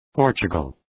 {‘pɔ:rtʃʋgəl}